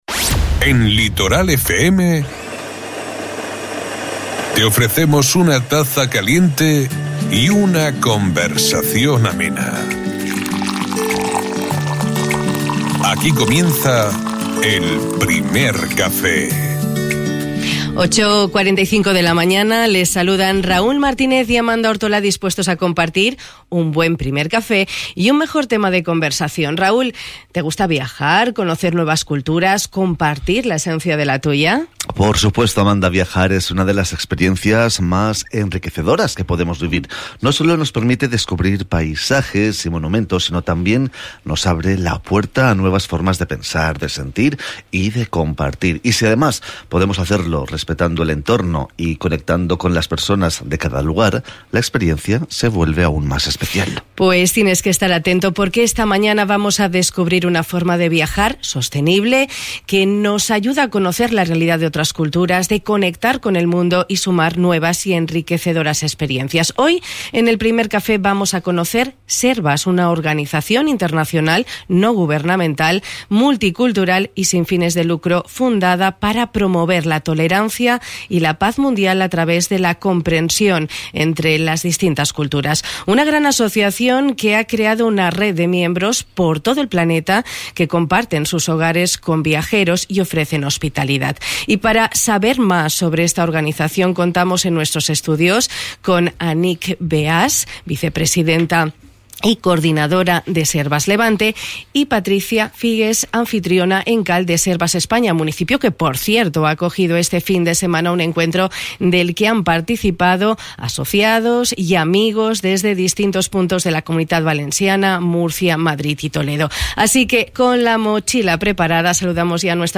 Así lo hemos podido comprobar en el Primer Café de hoy en el que nos han acompañado dos representantes SERVAS, una organización internacional, no gubernamental, multicultural y sin fines de lucro, fundada para promover la tolerancia y paz mundial a través de la comprensión entre las distintas culturas.